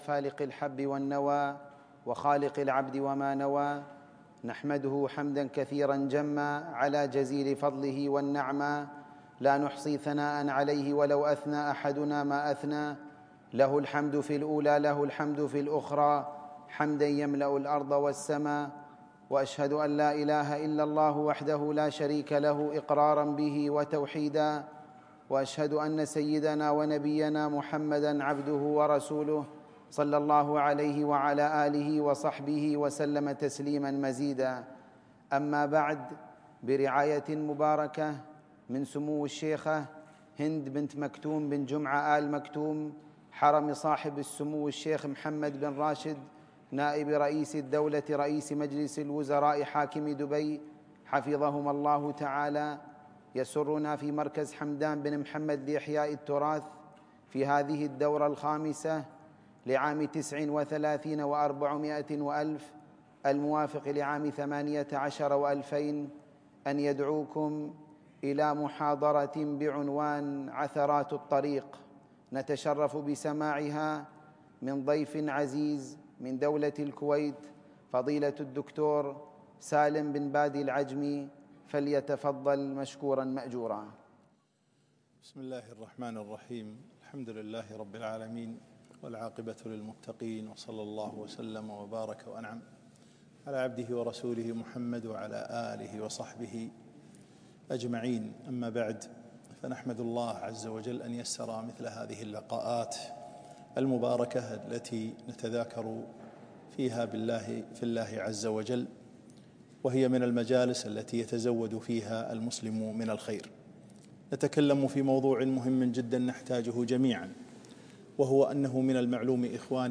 محاضرة - عثرات الطريق